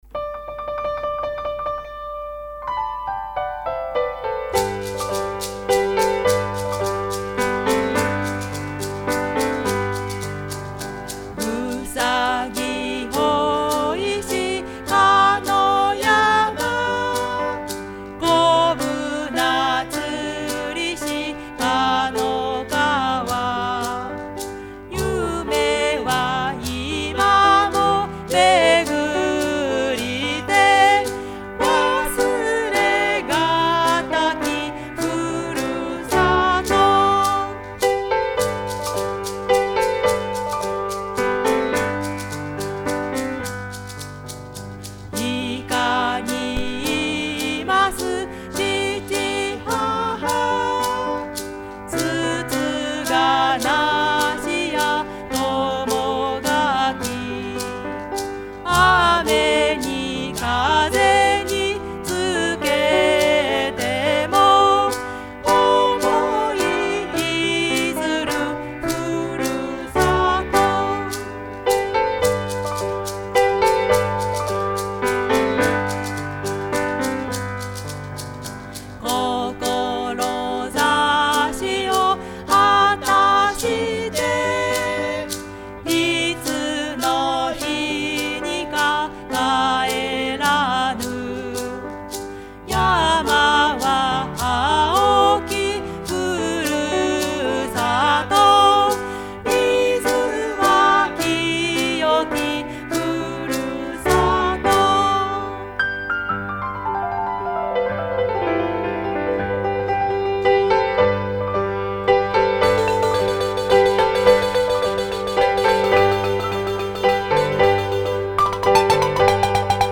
ふるさと：バラフォンと うた
ピアノと うた